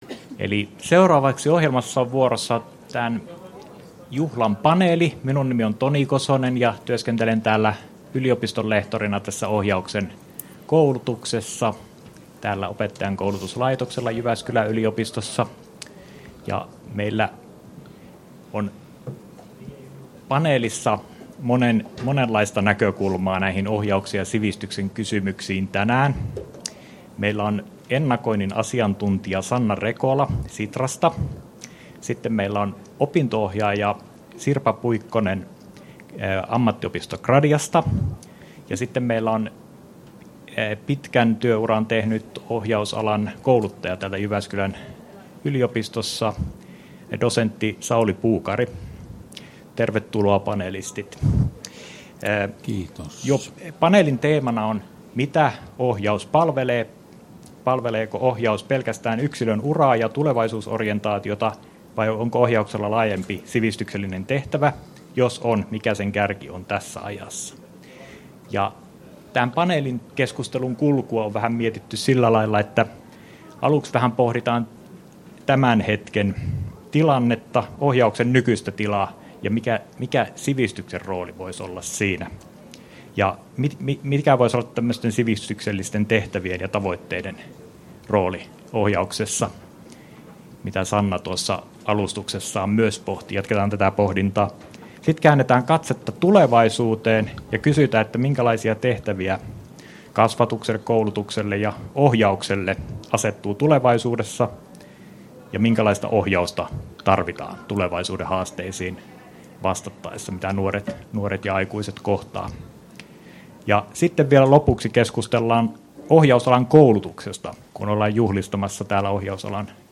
Ohjaus ja sivistys - Osa 2: Paneelikeskustelu — Moniviestin
Tallenne 17.11.2023: Ohjausalan koulutus opettajankoulutuksessa 50-vuotta juhlaseminaari